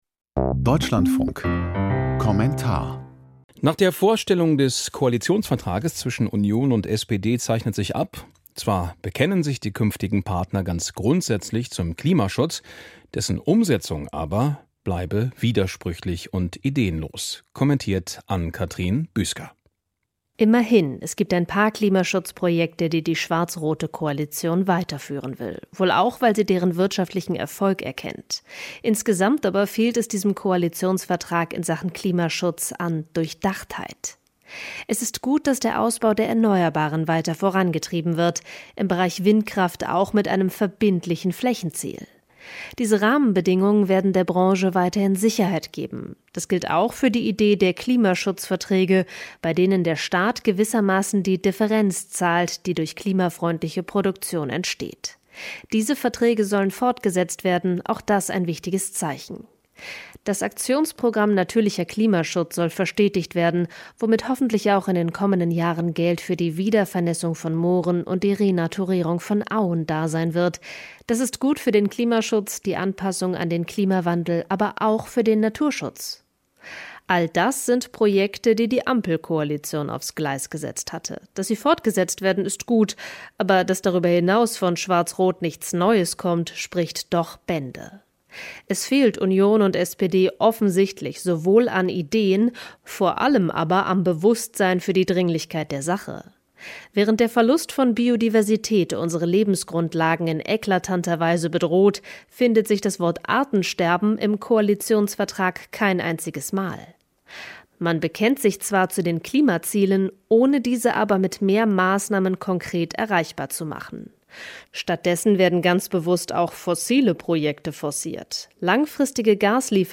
Kommentar zum Koalitionsvertrag